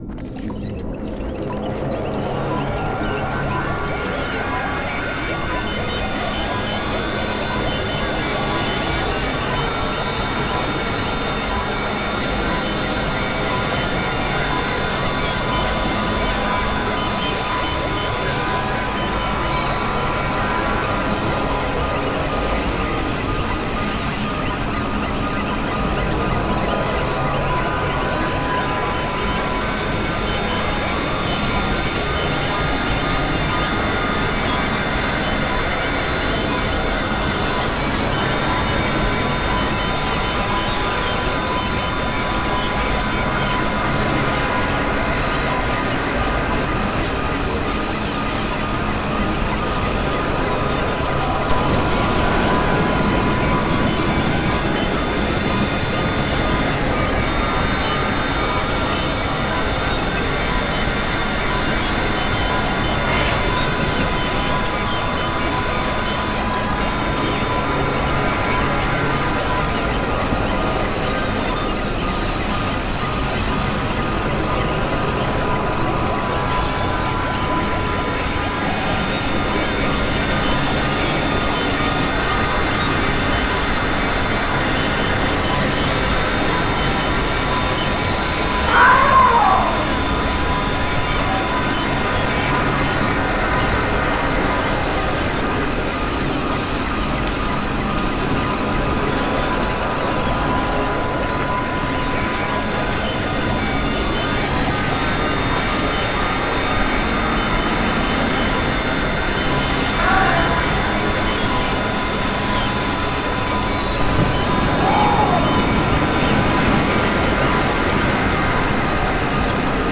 horn 01
aggressive air alarm civil defense disaster distant emergency sound effect free sound royalty free Sound Effects